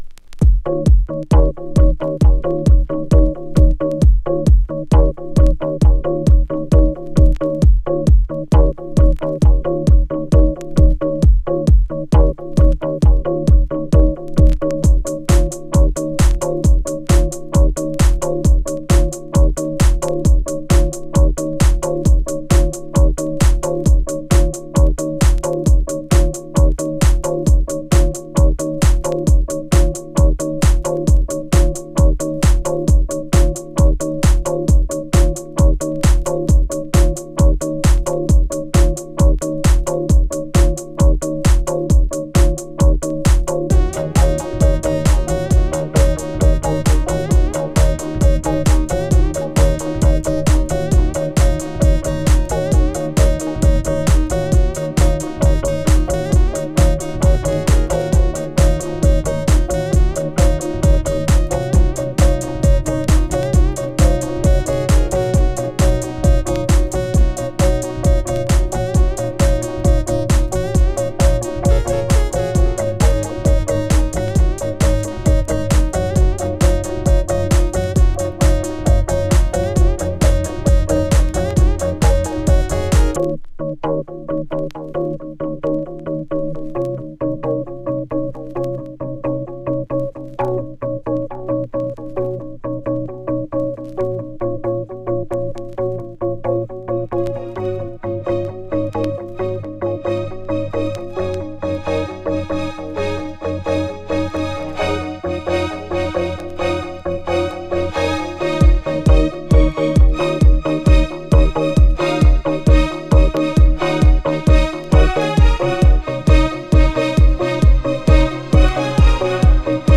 ドラマチックに奏でるストリングスとシンセが壮大に広がるテクノ・ナンバー
躍動するエレクトリックなトライバル・ビートが一直線に突き進む